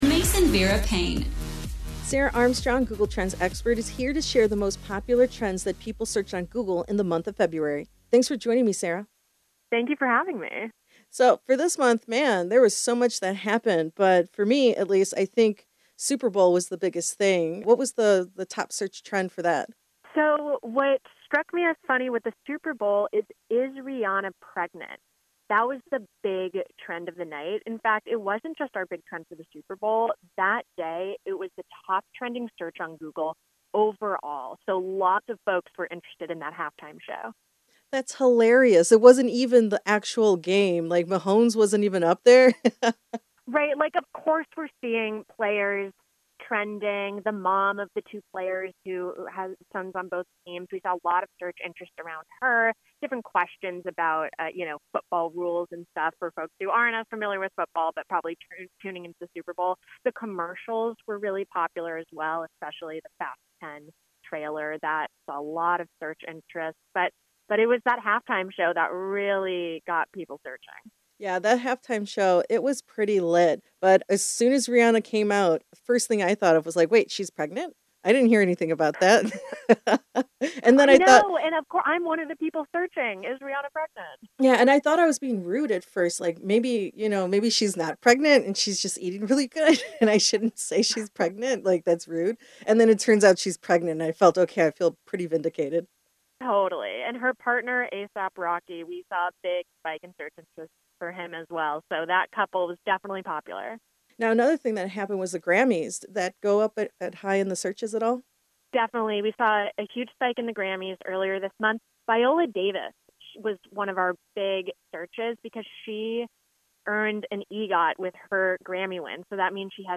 Google Trends February Transcription